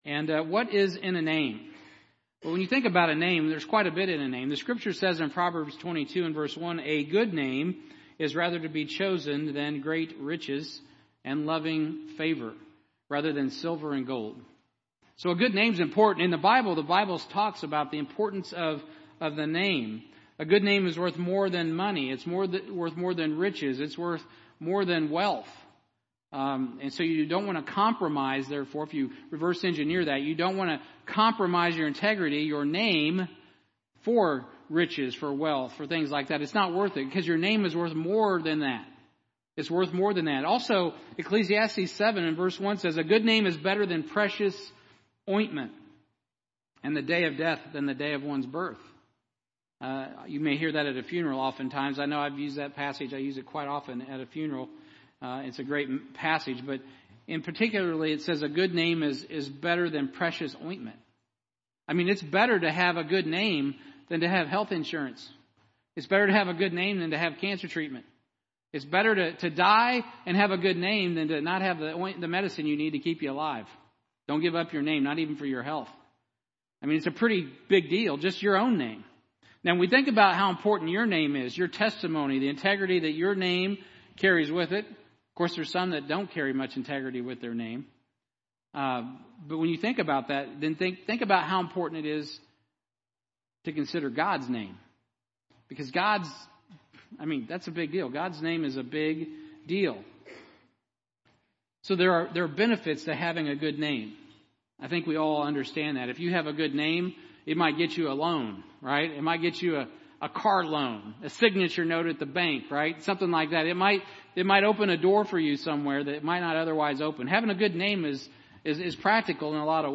Sermons | Heartland Baptist Fellowship